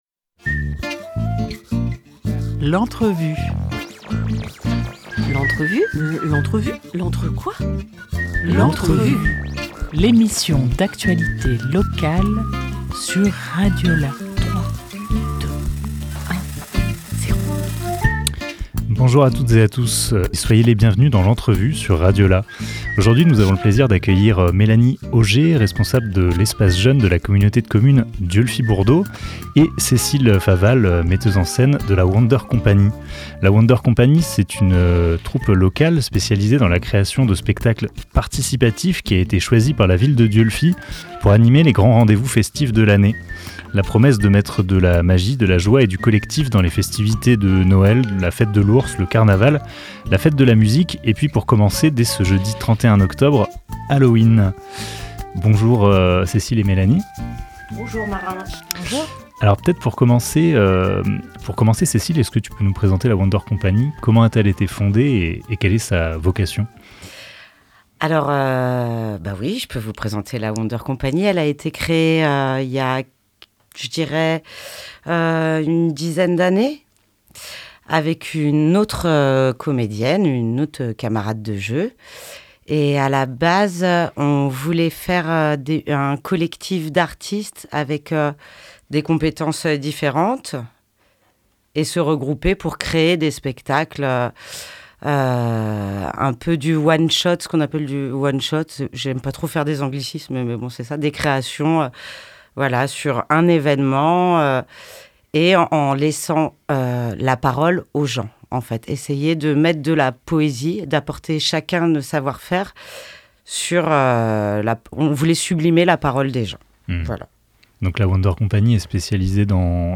28 octobre 2024 15:46 | Interview